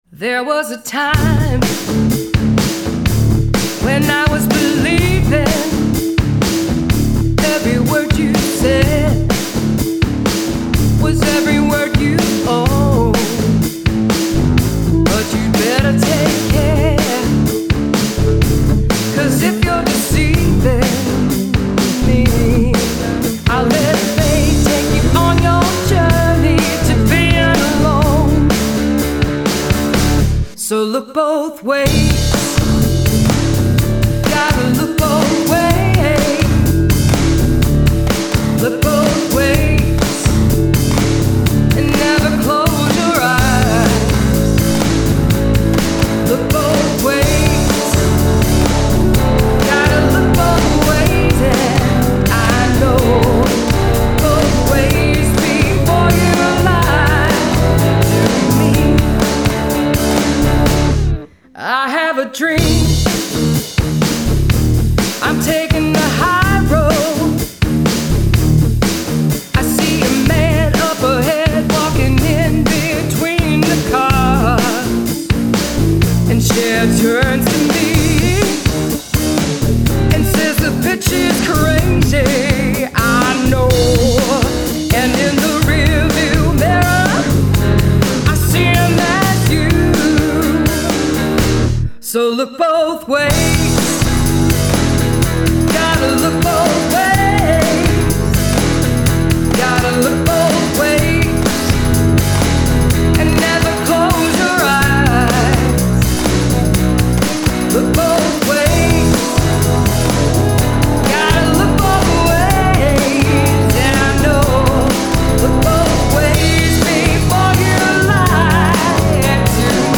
guitars, basses, keyboards, programming, vocals